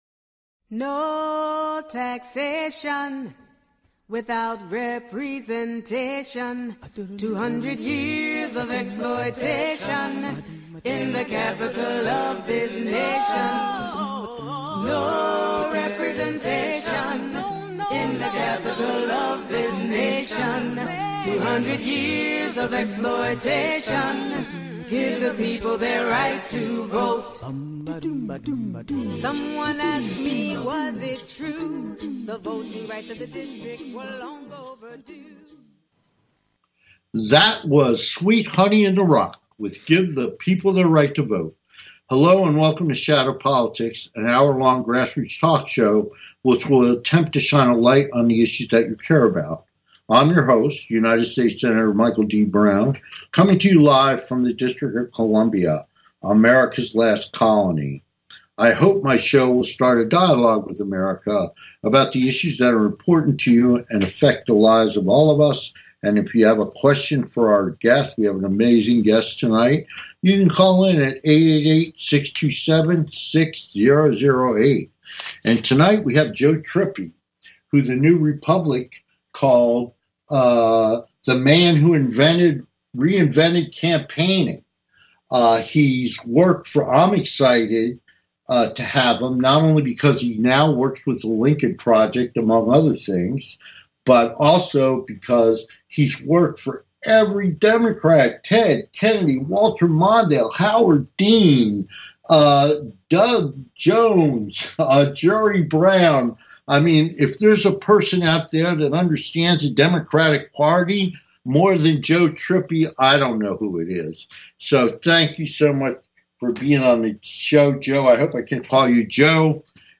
Guest, Joe Trippi, Democratic Strategist, on-air political analyst for MSNBC/NBC, CBS Evening News, FOX News and CNN